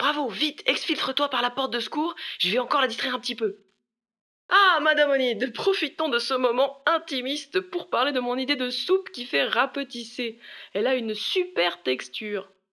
VO_LVL3_EVENT_Bravo reussite mission_02.ogg